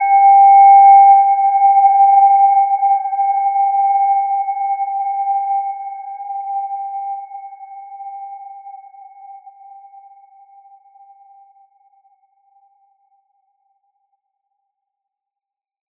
Gentle-Metallic-4-G5-mf.wav